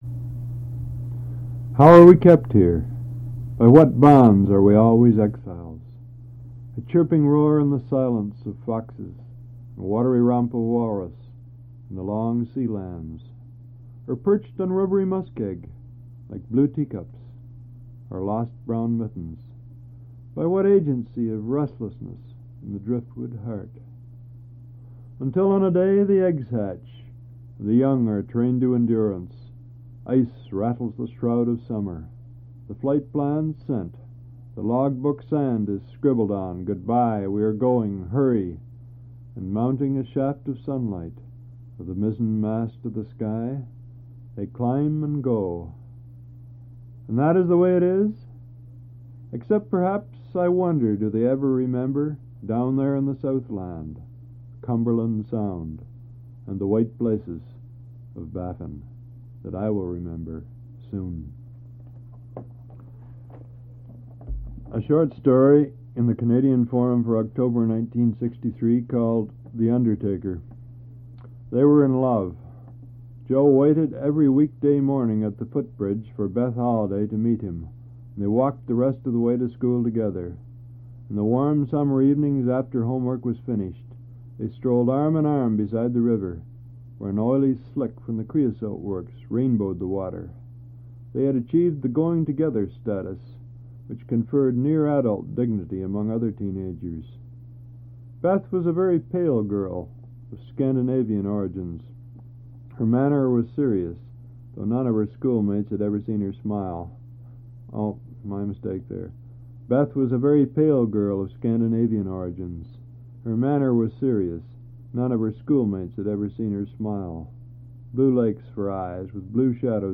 Al Purdy reads his article and a short story; TOC: "North of Summer" 0:00; The Undertaker 1:04; Total Duration 26:40.